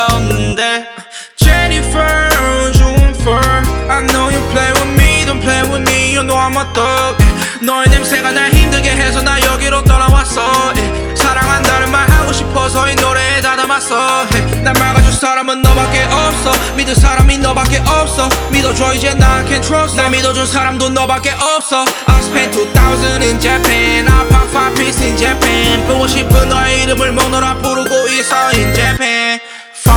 K-Pop Pop Hip-Hop Rap Korean Hip-Hop
Жанр: Хип-Хоп / Рэп / Поп музыка